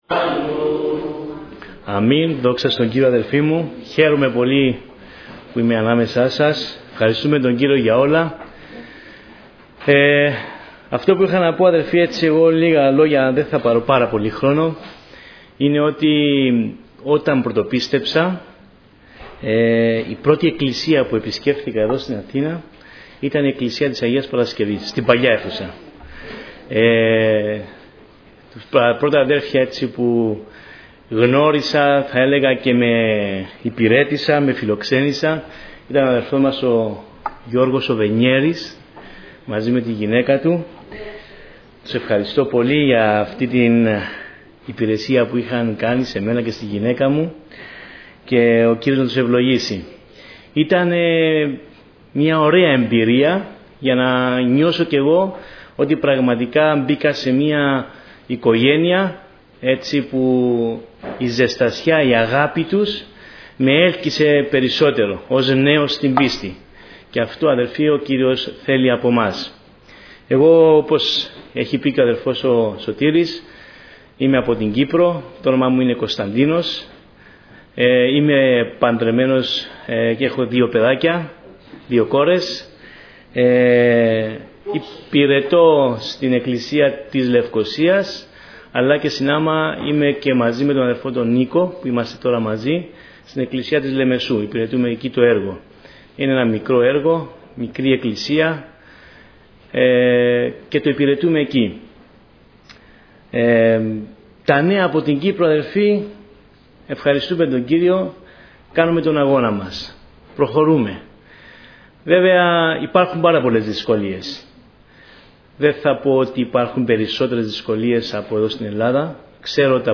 Στο Αρχείο Κηρυγμάτων θα βρείτε τα τελευταία Κηρύγματα, Μαθήματα , Μηνύματα Ευαγγελίου που έγιναν στην Ελευθέρα Αποστολική Εκκλησία Πεντηκοστής Αγίας Παρασκευής
Διάφοροι Ομιλητές Ομιλητής: Διάφοροι Ομιλητές Λεπτομέρειες Σειρά: Κηρύγματα Ημερομηνία: Παρασκευή, 24 Μαρτίου 2017 Εμφανίσεις: 316 Γραφή: Προς Γαλάτας 5:16-5:18 Λήψη ήχου Λήψη βίντεο